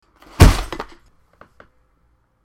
box-open.16e7d0c573153dc5adfa.mp3